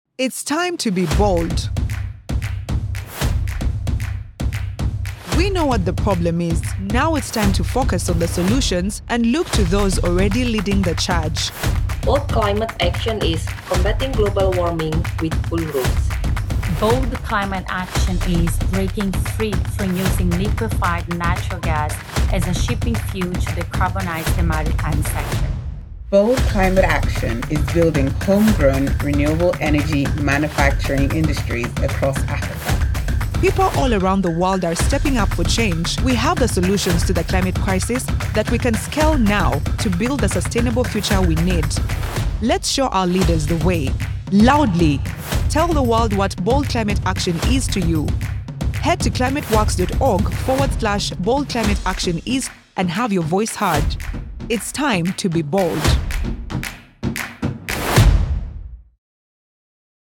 English (African)
Corporate Videos
PreSonus AudioBox 96 Studio Bundle
Young Adult
Middle-Aged